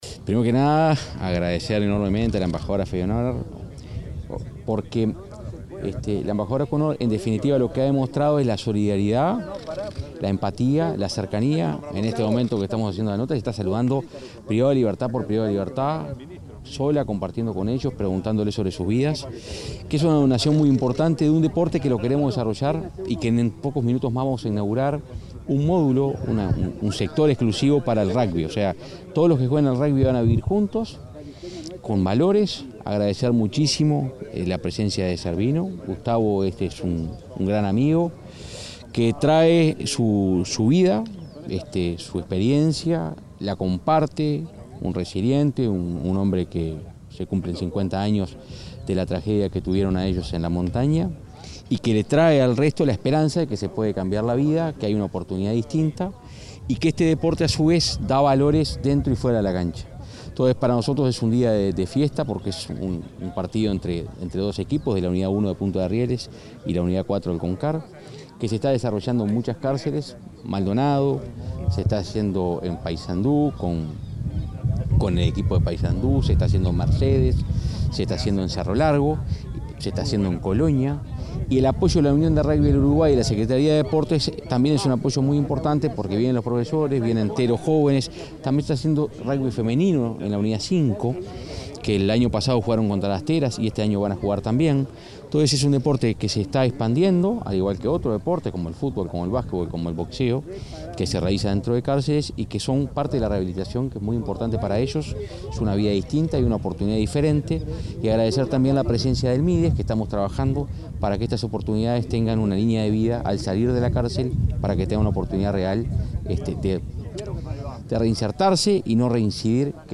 El director de Convivencia del Ministerio del Interior, Santiago González, dialogó con la prensa sobre el tema.